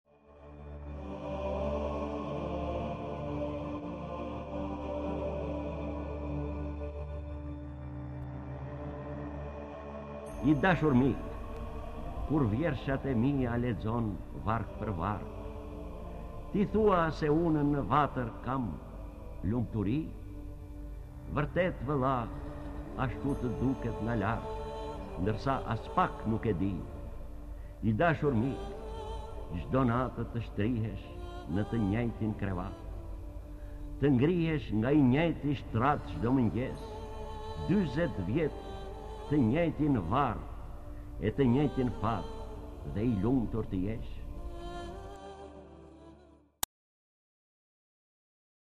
D. AGOLLI - LETËR Lexuar nga D. Agolli KTHEHU...